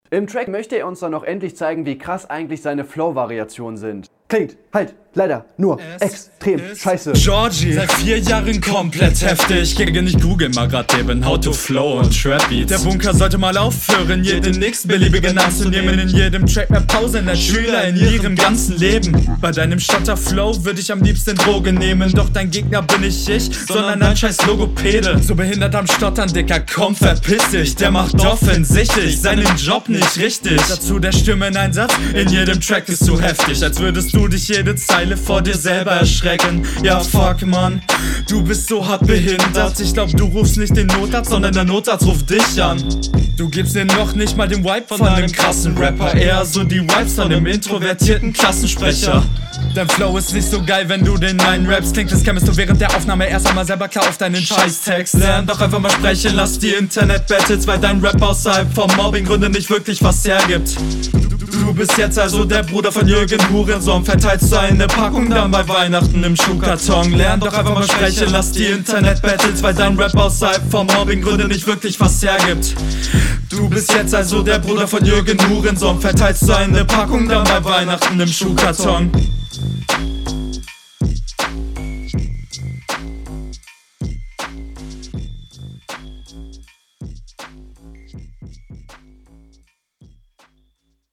Doubles oft nicht sauber aufgenommen